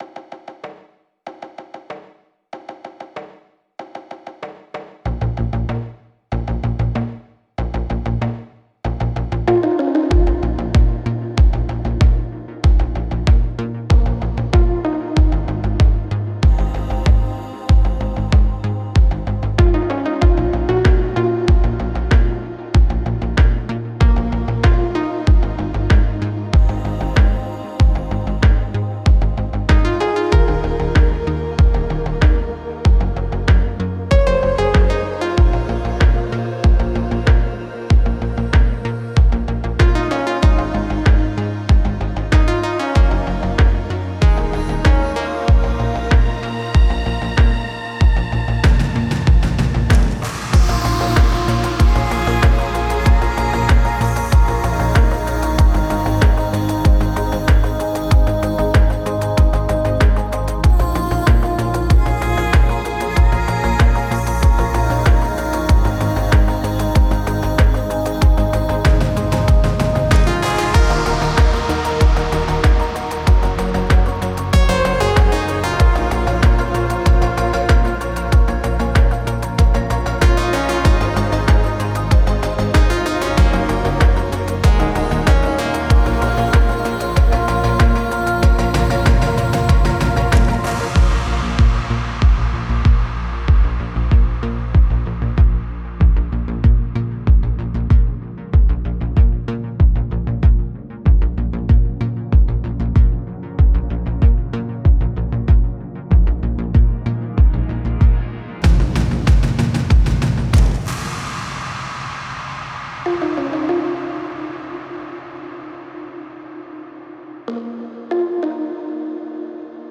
который сочетает в себе элементы эмбиента и транс-музыки.